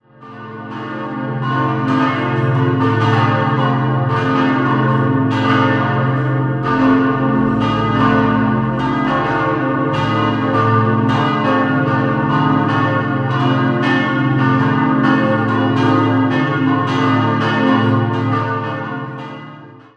Die jetzige Ausmalung der Kirche geschah durch Fidelis Schabet 1861-1863 Idealquartett: b°-des'-es'-ges' Die Glocken wurden 1951 von der Gießerei Czudnochwosky in Erding gegossen.